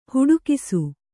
♪ huḍukisu